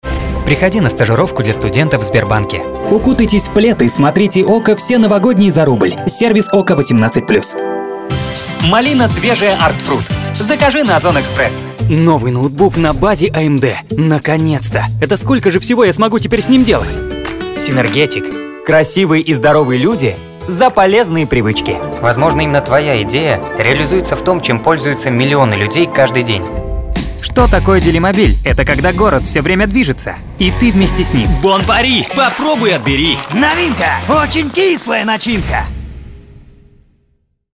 Описание голоса: молодежный, энергичный, узнаваемый, яркий, харизматичный, нежный, ласковый, добрый, молодой, известный голос, мультяшный, игровой, дисней, мультики, приятный тембр.
Тракт: микрофон Октава МК-319 аудиокарта M-Audio C400
Демо-запись №1 Скачать